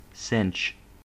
/sɪntʃ/) – złącze elektroniczne używane w urządzeniach elektrycznych elektroniki konsumenckiej np. amplituner, magnetowid, gramofon, magnetofon.
En-us-cinch.ogg.mp3